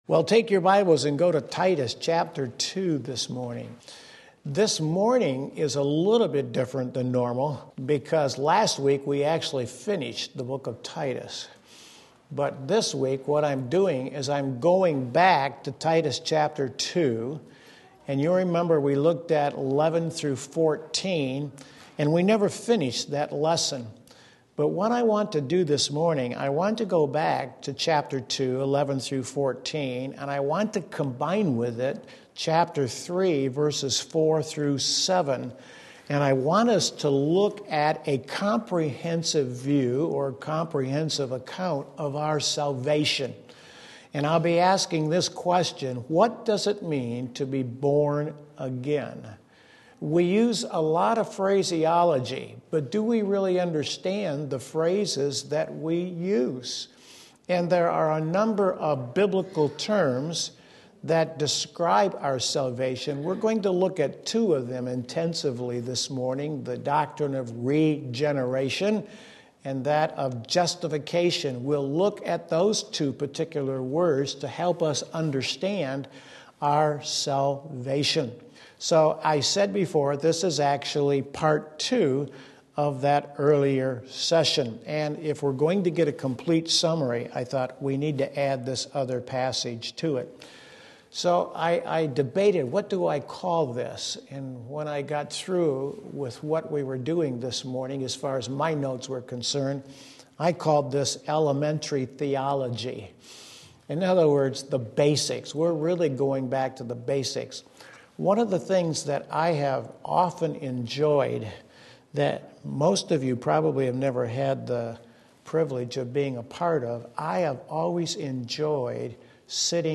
This lesson is Part 2 of the session on Titus 2:11-14.